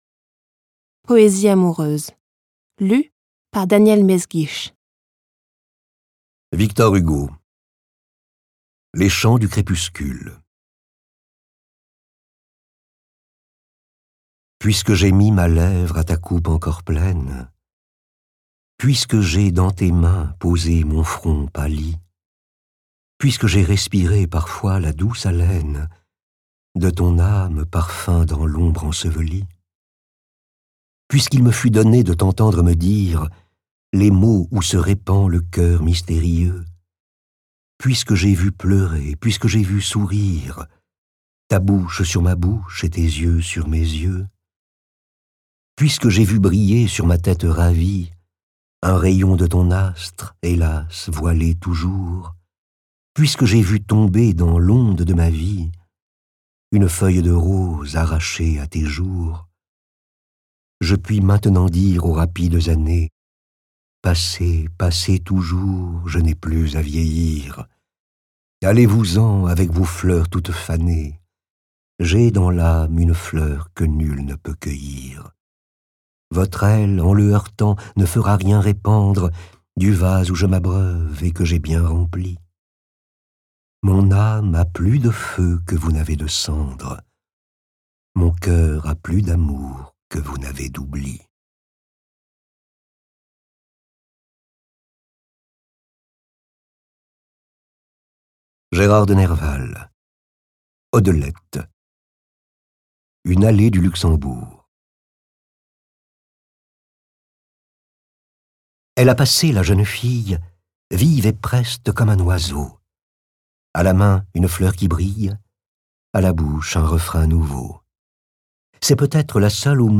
Les plus grands auteurs, hommes et femmes, sont réunis dans cette anthologie qui propose plus de quarante poèmes d’amour. Daniel Mesguich met tout son talent d’interprète et la justesse de sa diction au service des poètes et de la langue amoureuse en ses infinies variations.